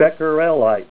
Say BECQUERELITE Help on Synonym: Synonym: ICSD 201364   PDF 29-389